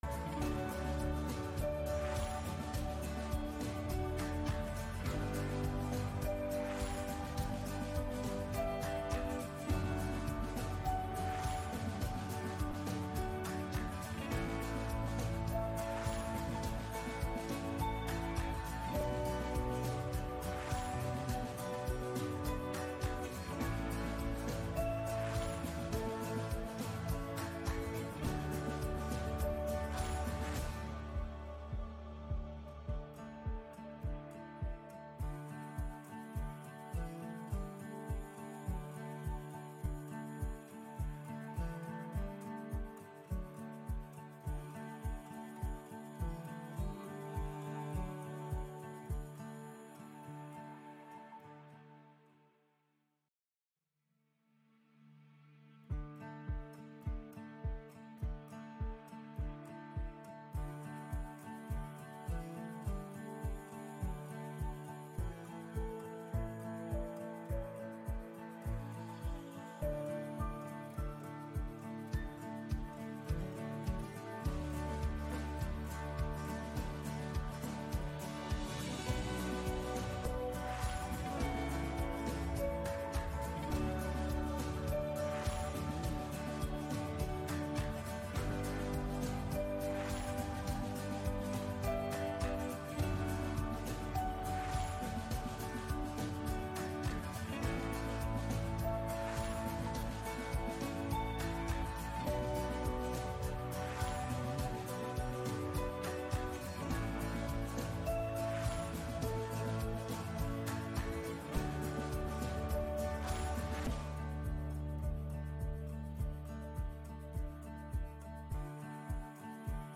Gottesdienst am 27. Juli 2025 aus der Christuskirche Altona